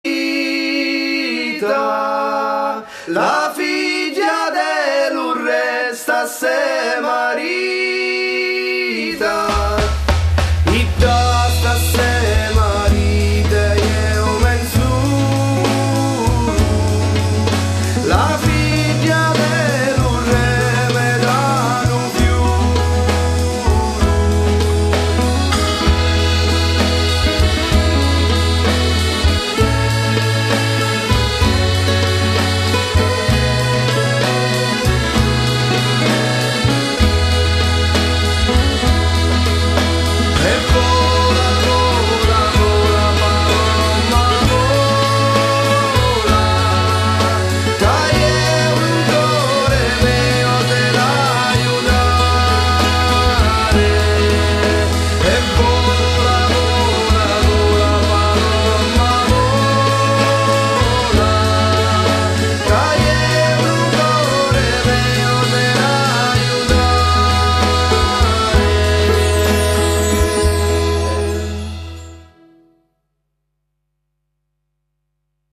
Voce e Chitarra
Fisarmonica
Percussioni